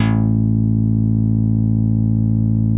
MICROFUNK A2.wav